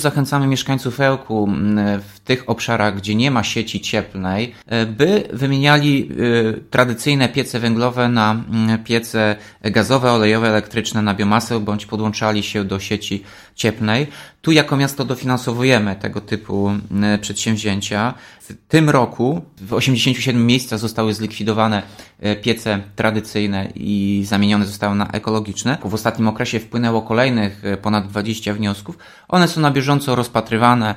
Tomasz Andrukiewicz – prezydent Ełku.